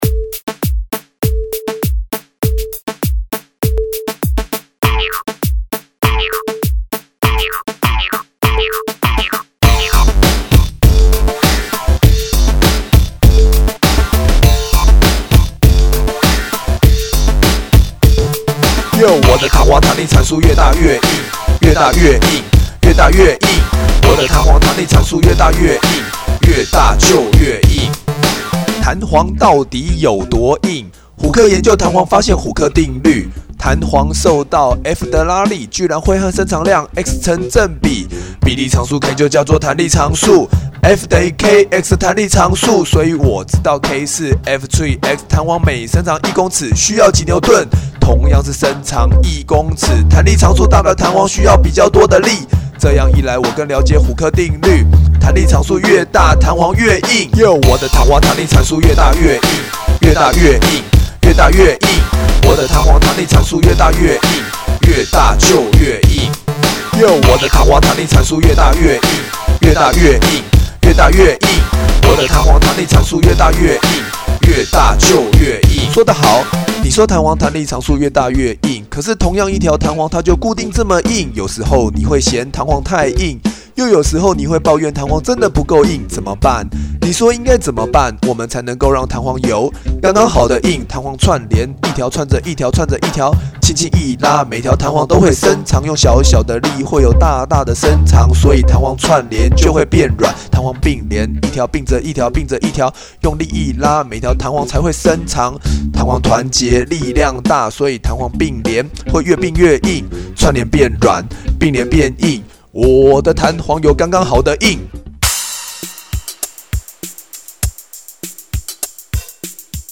自編自唱饒舌歌(2007.12) [歌曲下載]